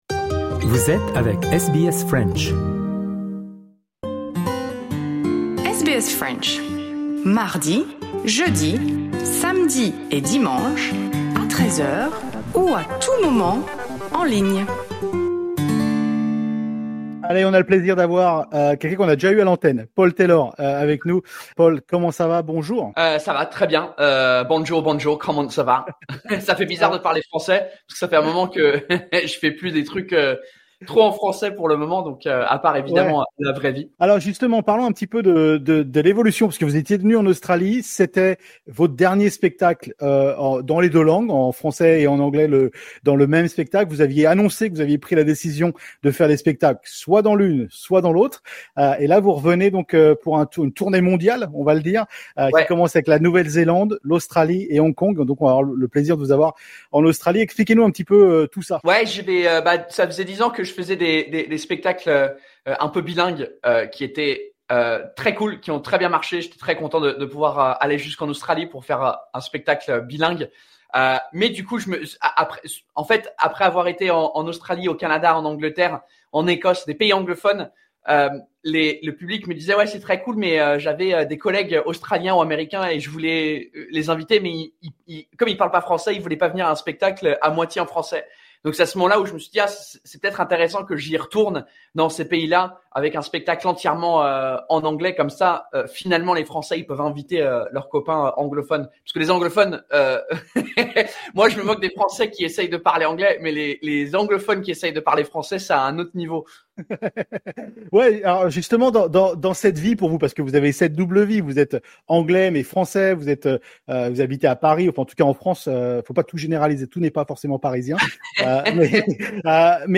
Dans cet entretien, Paul Taylor, humoriste franco-britannique, partage son parcours en France, ses spectacles bilingues et sa décision de jouer uniquement en anglais.